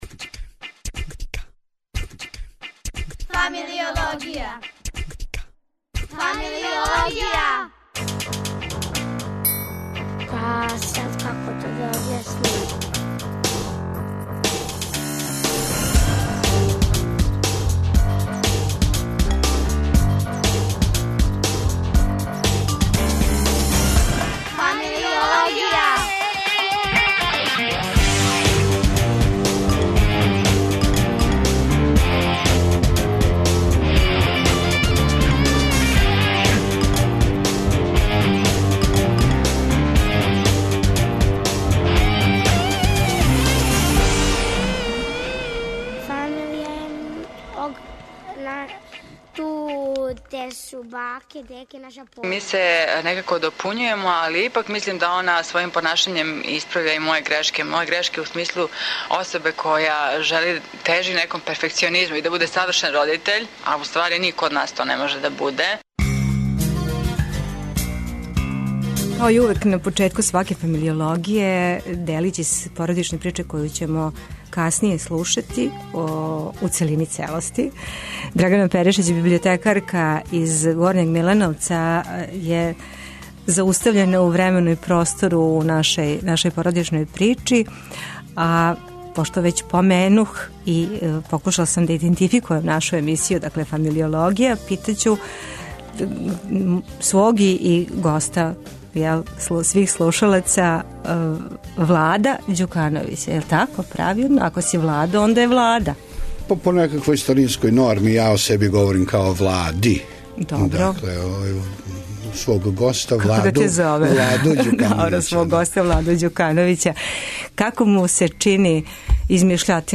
Гост у студију је